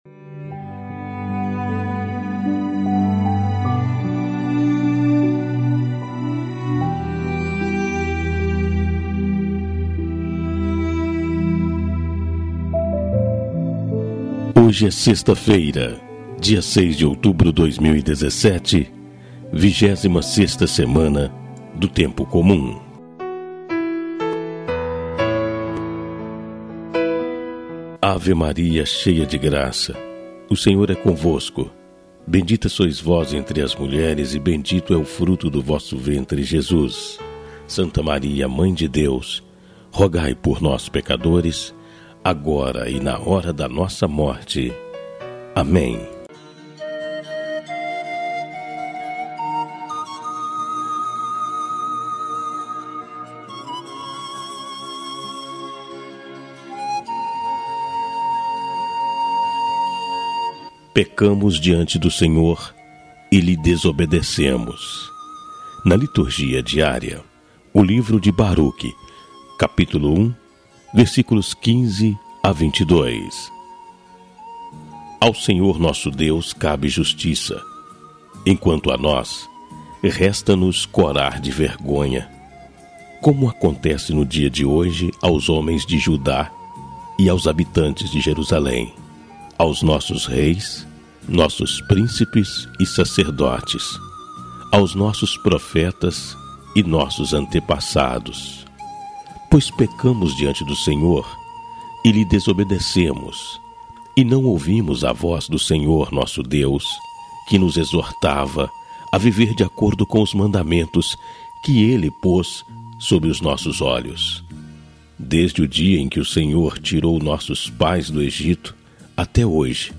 Leitura do Livro de Baruc 1